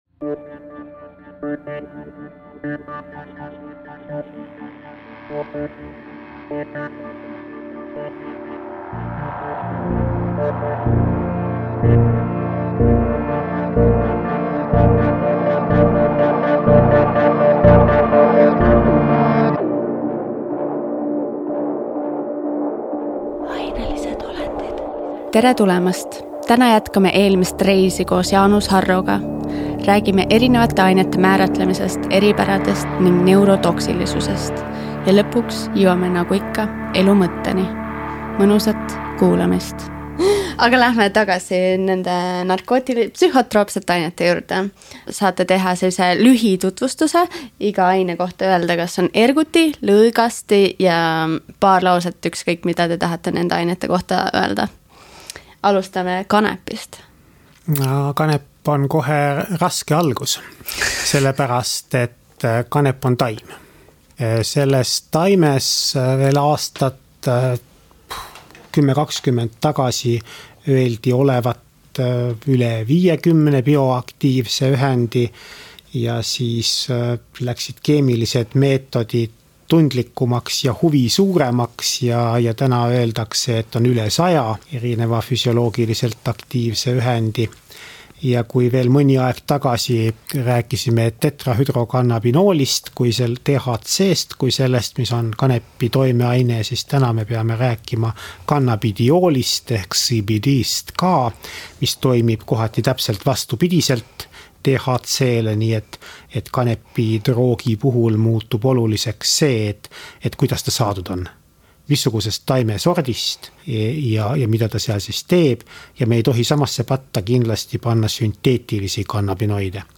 Vestlus narkootikumide tarvitaja ja teadlase vahel.